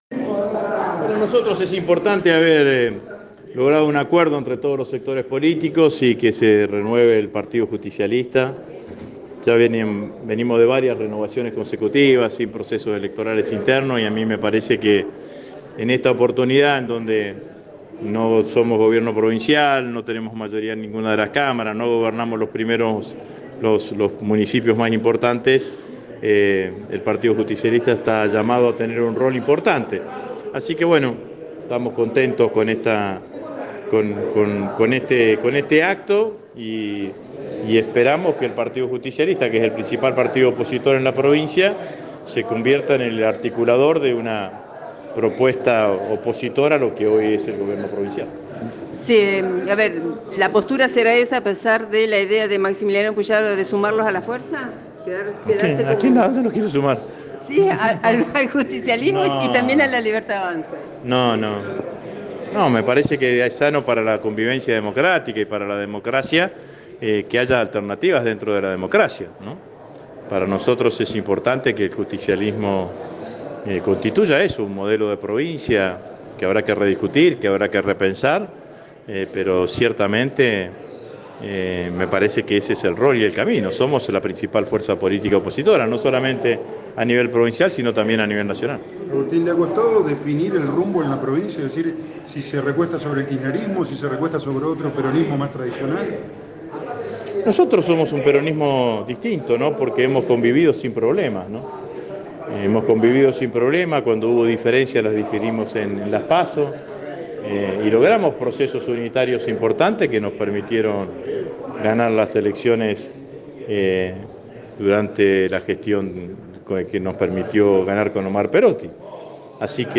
El verense dialogó con Radio EME y realizó un análisis de la actualidad de la Argentina.